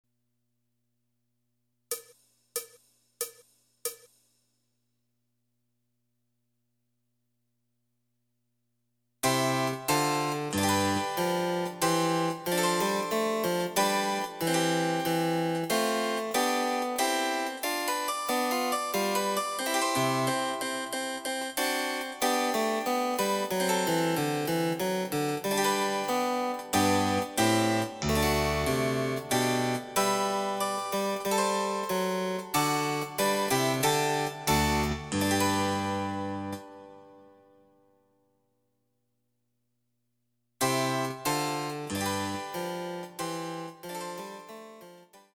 ★フルートの名曲をチェンバロ伴奏つきで演奏できる、「チェンバロ伴奏ＣＤつき楽譜」です。
試聴ファイル（伴奏）